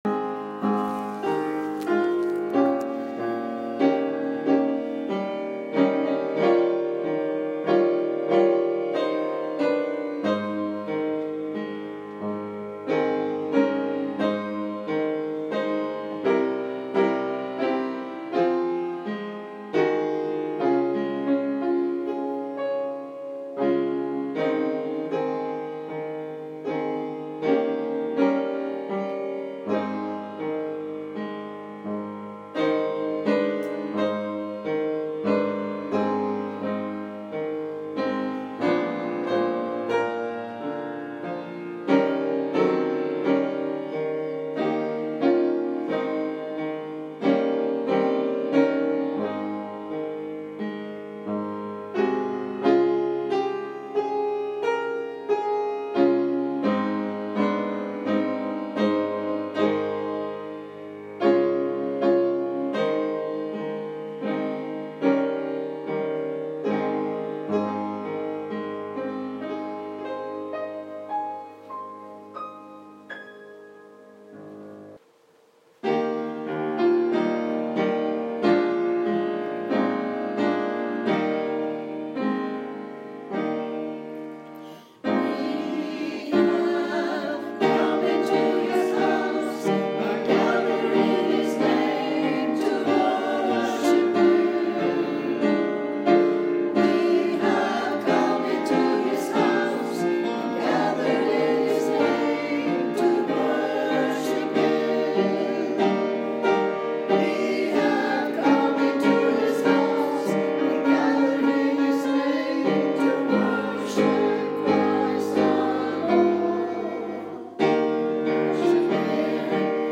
First Baptist Church of Ayden, NC - Audio Sermons
2023-03-26 Sunday Worship Program